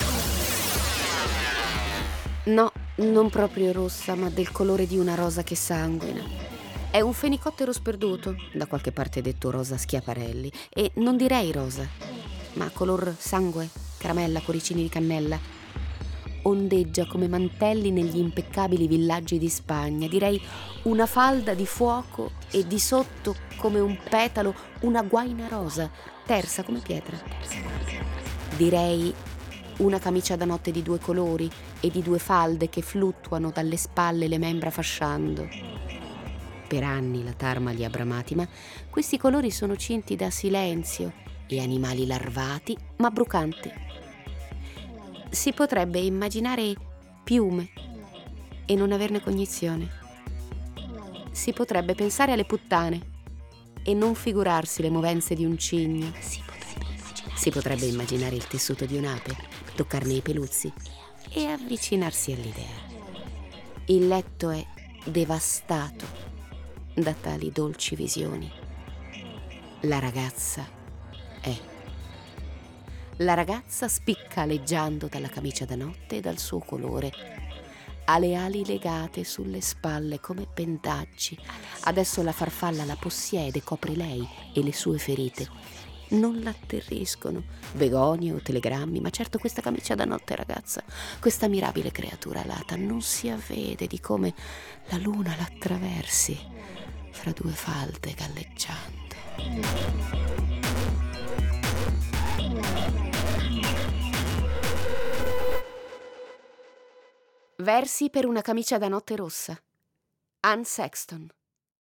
Letto da